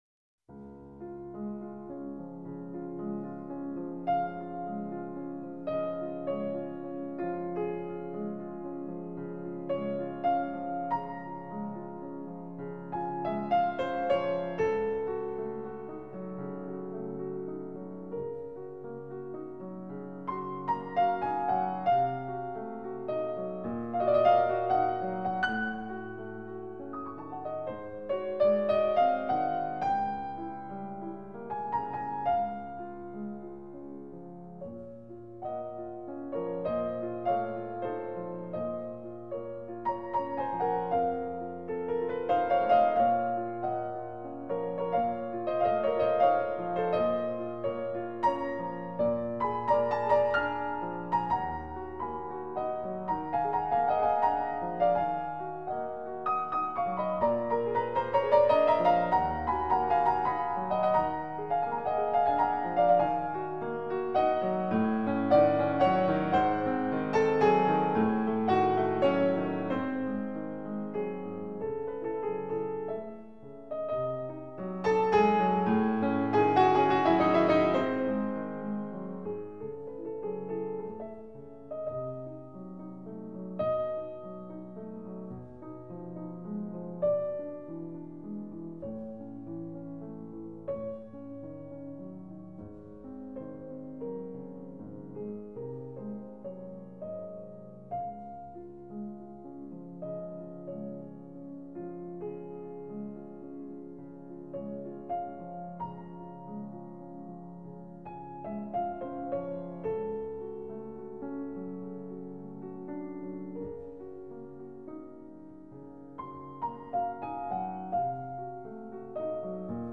06-Nocturne-.mp3